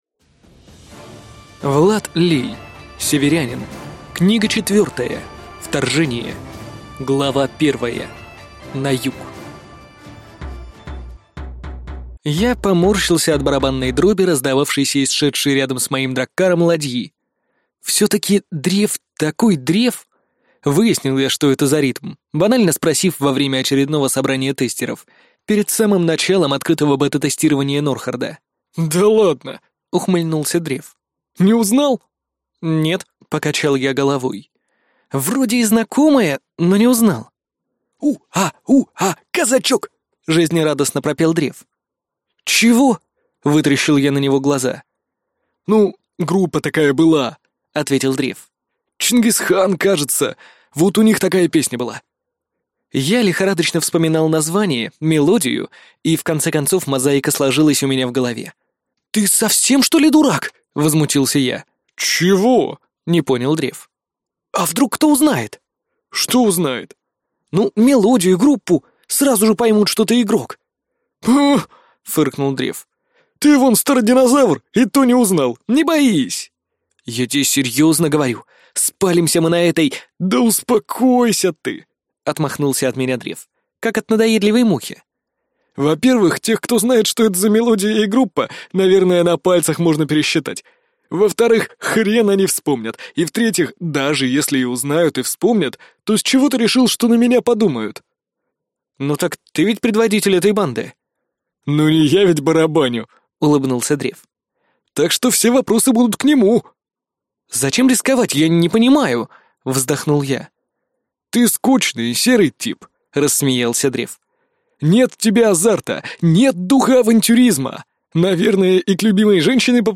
Аудиокнига Северянин. Книга 4. Вторжение | Библиотека аудиокниг